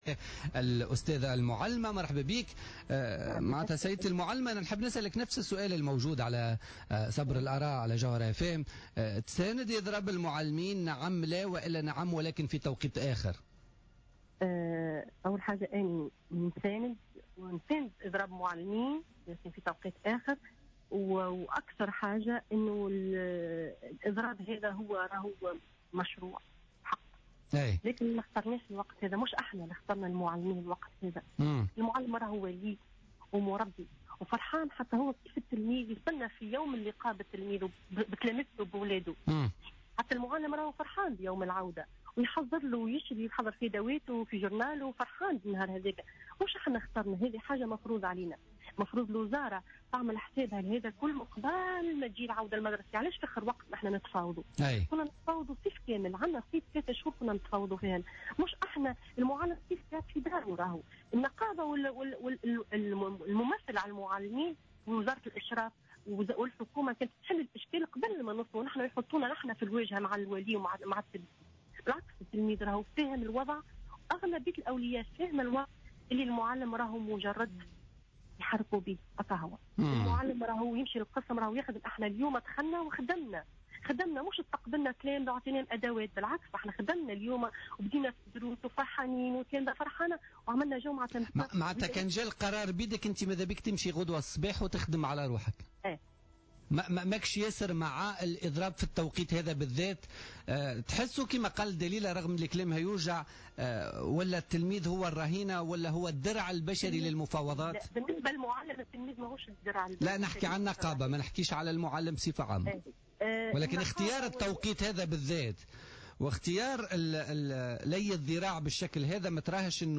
مداخلة معلمة في بوليتيكا